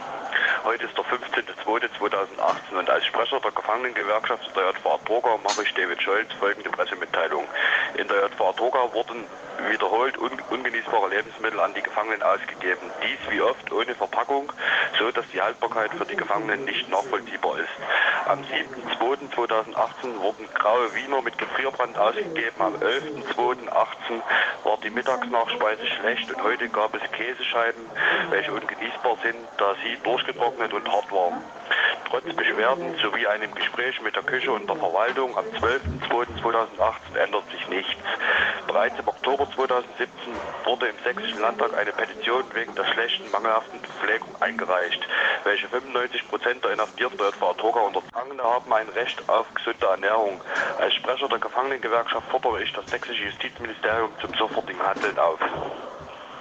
Audio Statment